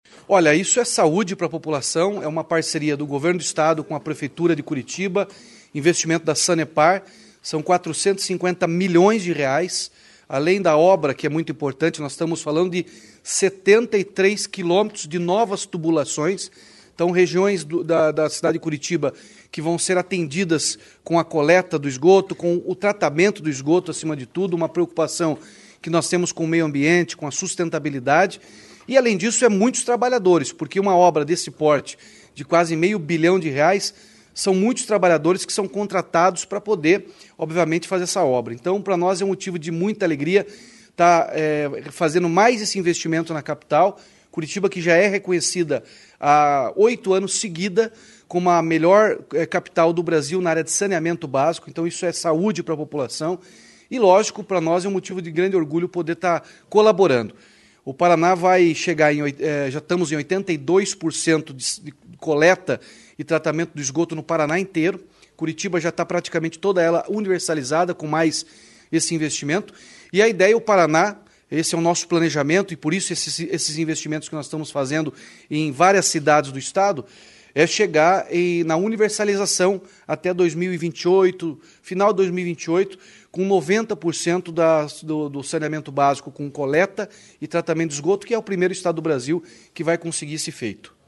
Sonora do governador Ratinho Junior sobre os investimentos de R$ 456 milhões da Sanepar para Curitiba